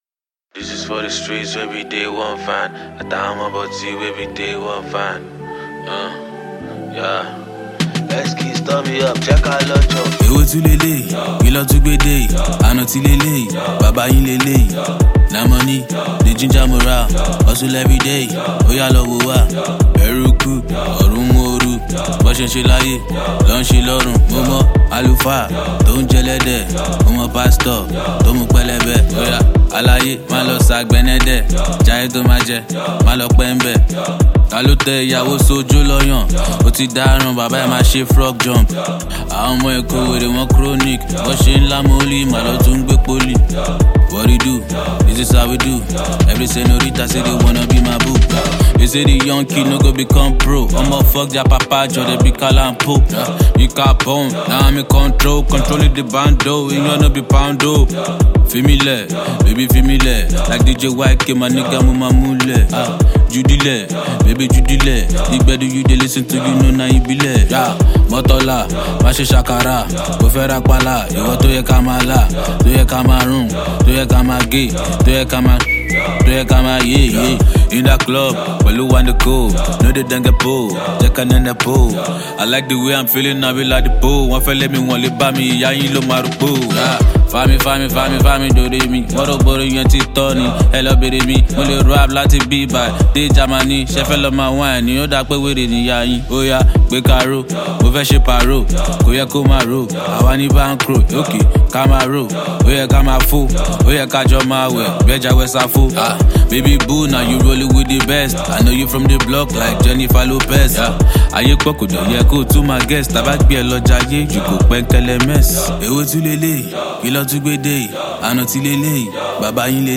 Nigerian rap